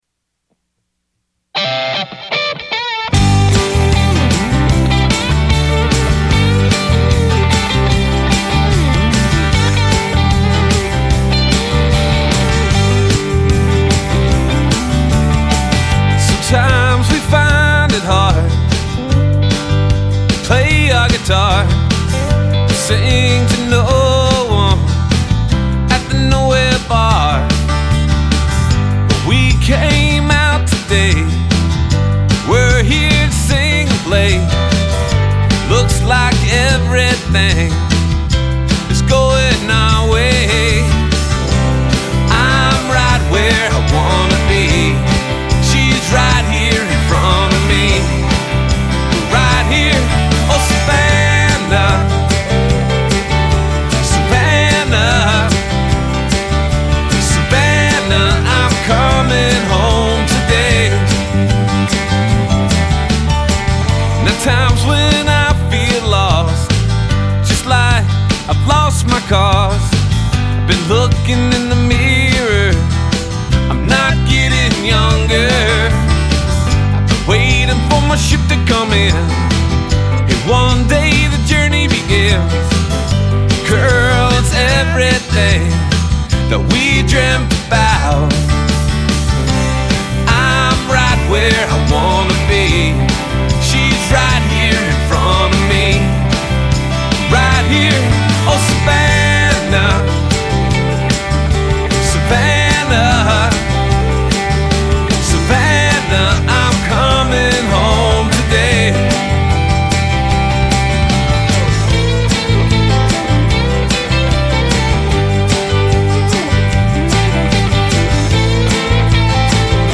Alt. Country Tune